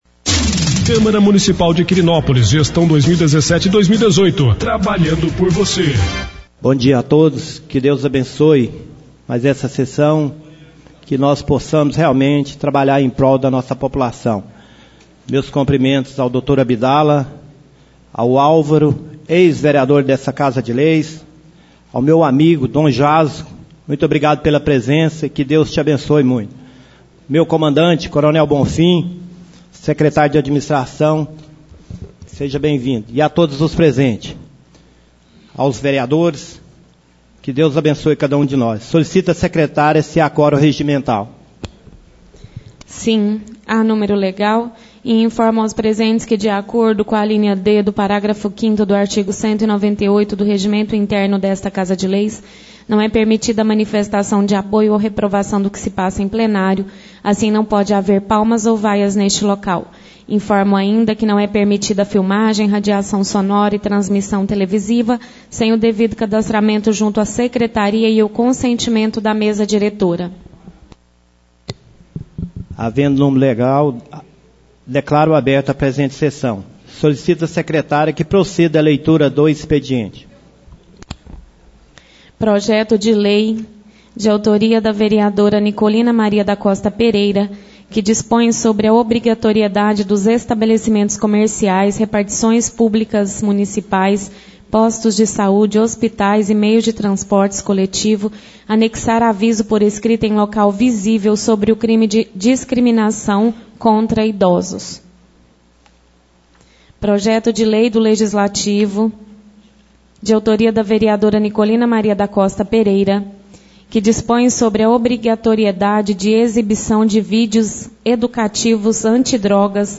Áudios das Reuniões